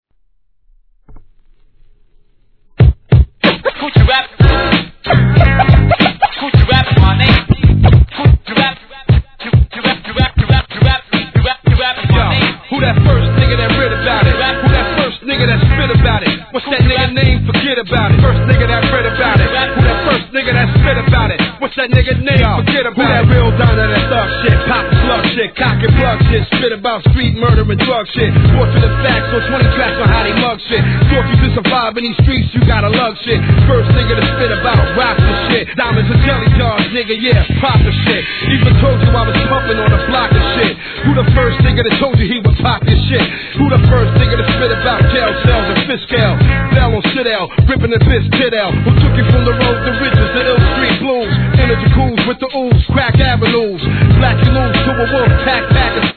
HIP HOP/R&B
ヴォコーダーを聴かせまくったフックでのKEEP IT REALのG魂!!!